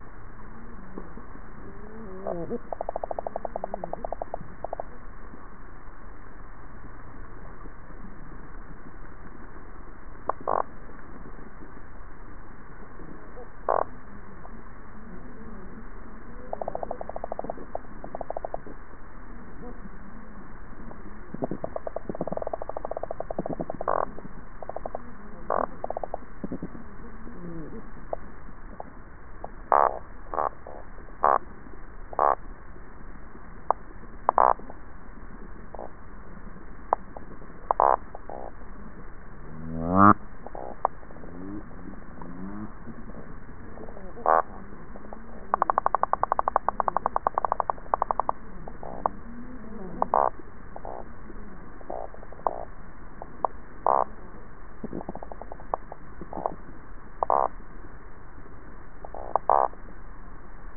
Au cours de ces 5 dernières années, grâce à des hydrophones, l’équipe liégeoise a effectué de nombreux enregistrements acoustiques sous-marins autour de six îles polynésiennes à 20, 60 et 120 mètres de profondeur.
Mettez un casque et écoutez cette bande sonore de poissons récifaux enregistrée par 120 m de profondeur à Tikehau, un atoll polynésien :